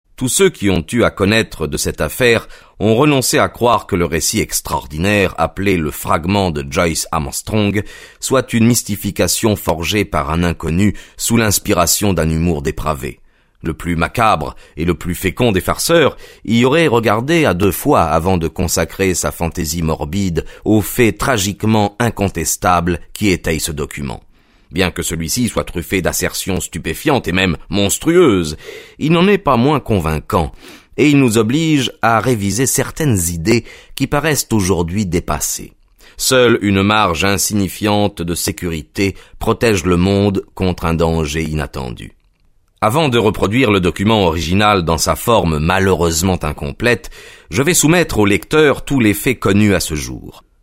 Click for an excerpt - L'horreur du plein ciel de Arthur Conan Doyle